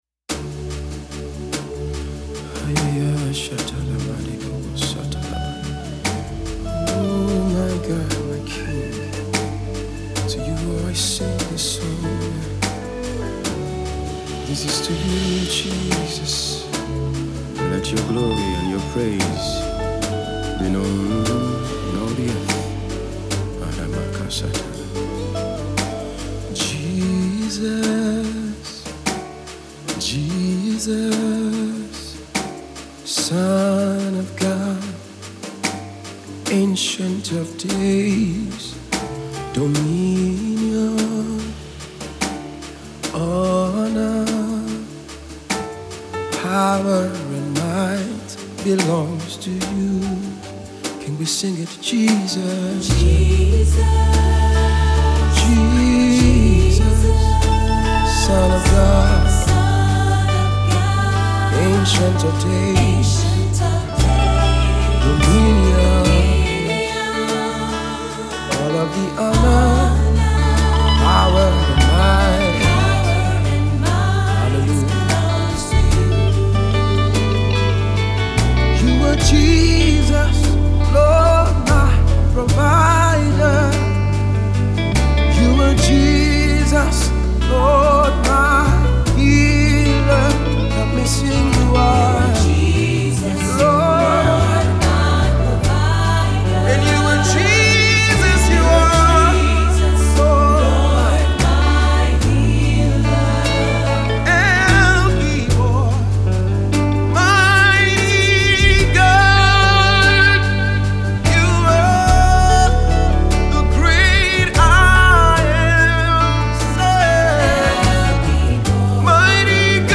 New Single
gospel